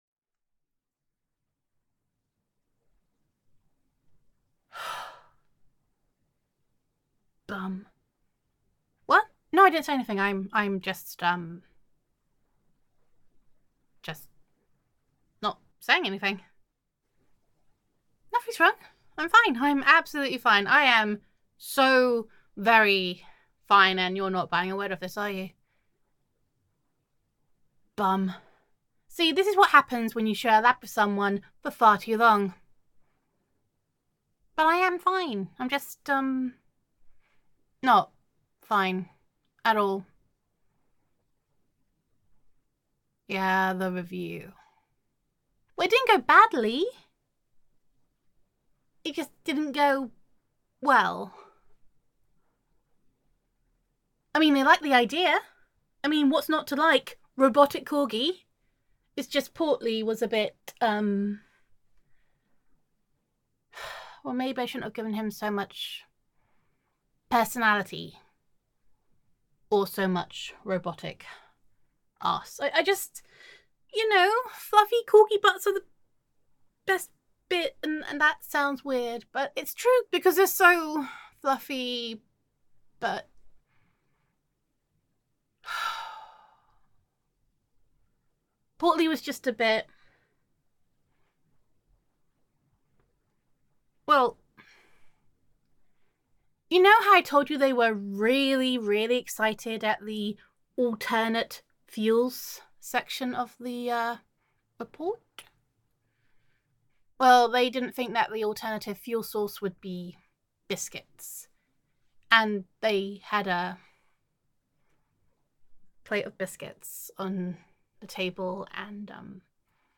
[Flustered Sweetheart][Hapless Scientist Roleplay]